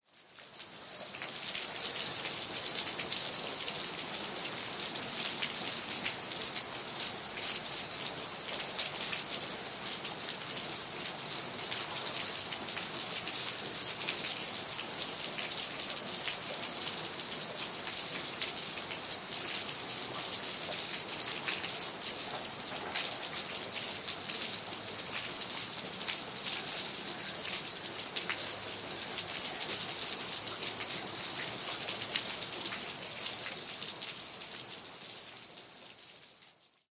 暴雨
描述：雨落在混凝土上的记录。
Tag: 现场记录 暴雨